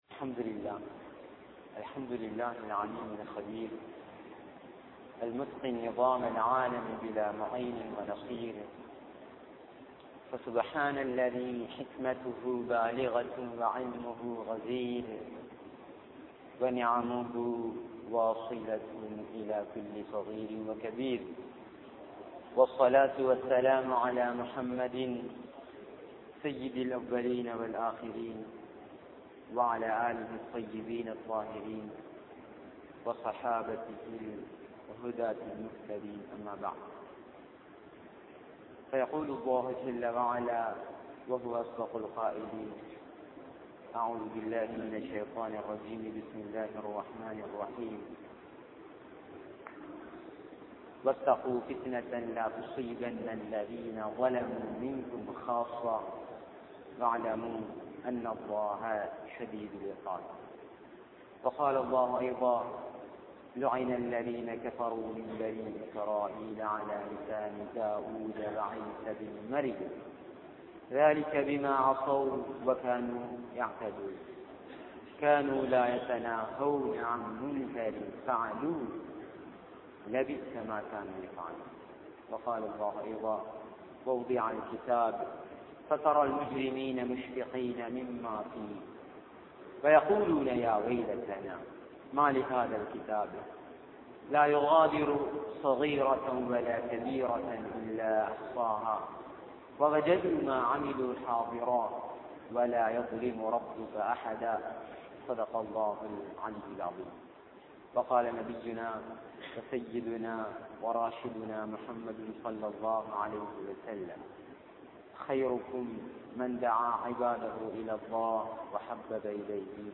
Islam Ethir Paarkum Vaalifarhal (இஸ்லாம் எதிர்பார்க்கும் வாலிபர்கள்) | Audio Bayans | All Ceylon Muslim Youth Community | Addalaichenai
Thaqreen Jumua Masjidh